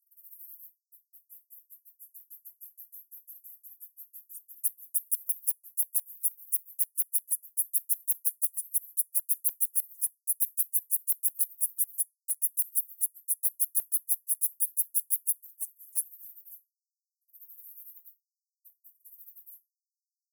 This waveform is a 20 s excerpt of the 1 min 43 s audio file accessible above. waveform